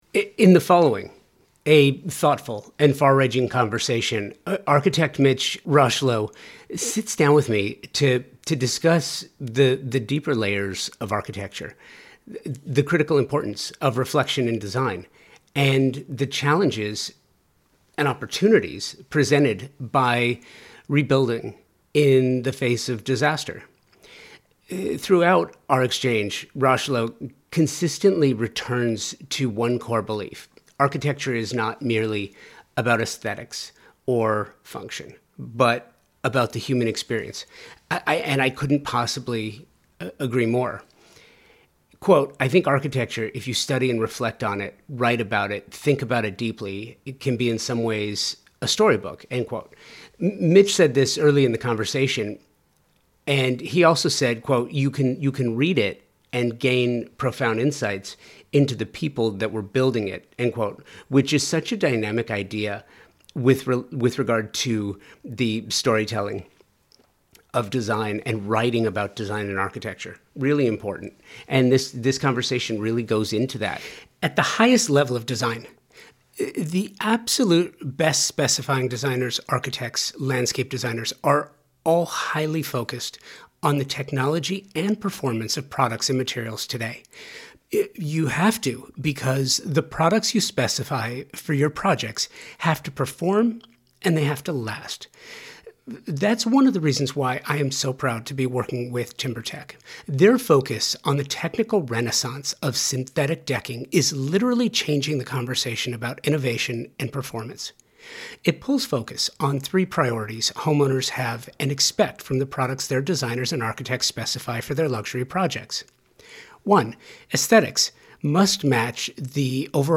The conversation centered around ROST Architects intentions and approach to creating designs for the human experience. Listen to the episode or read the conversation to hear the ideas shared and explored during this thought-provoking conversation.